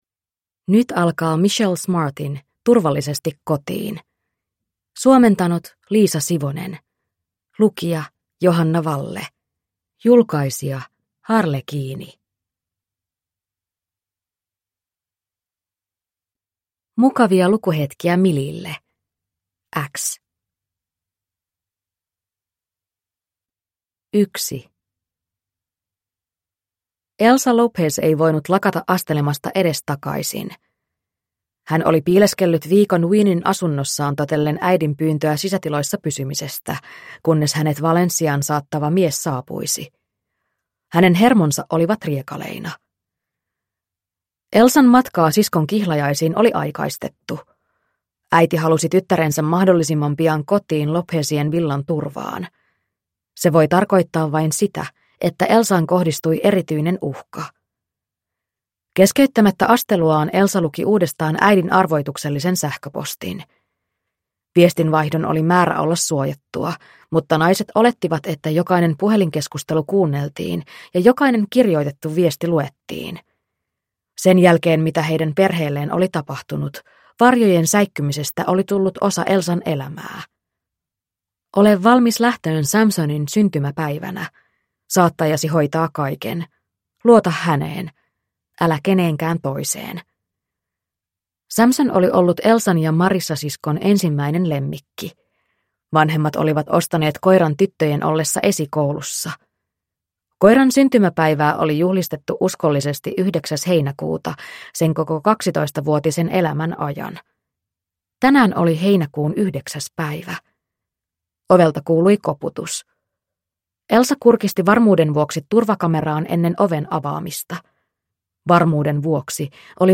Turvallisesti kotiin (ljudbok) av Michelle Smart